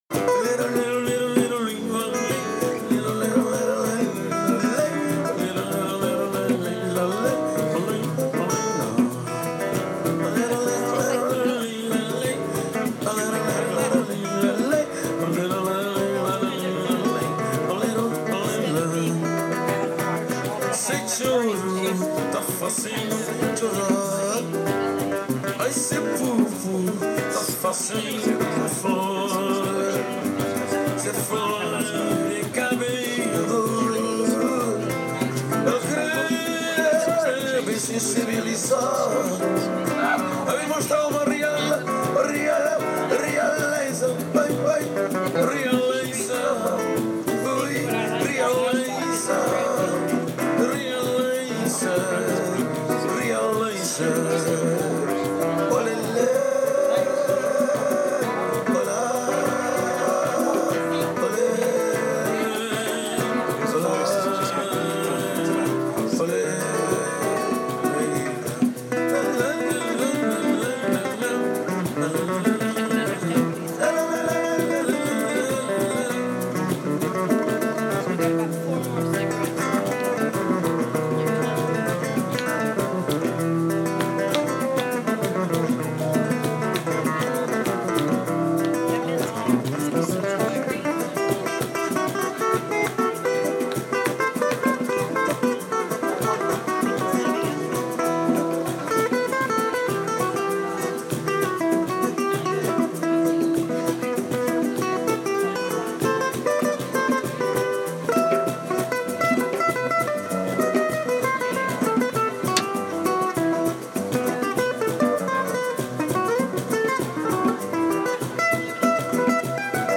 Killer live music at the Mirador - a beer, a brandy, a journal - life is awesome.